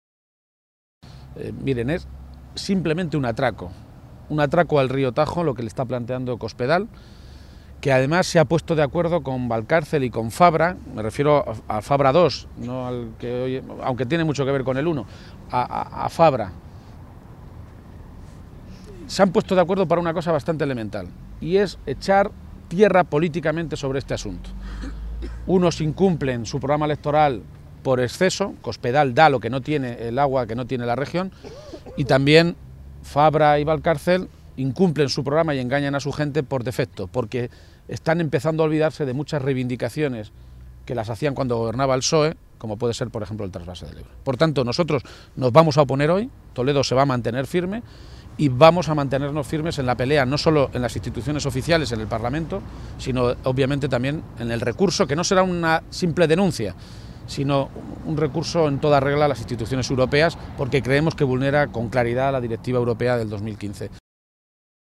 A preguntas de los medios de comunicación esta mañana, en Toledo, García-Page ha sostenido que el Plan de Cuenca se queda ya en nada porque, en paralelo, el Congreso y el Senado están tramitando dentro de la Ley de Impacto Ambiental el llamado Memorándum sobre el Tajo.
Cortes de audio de la rueda de prensa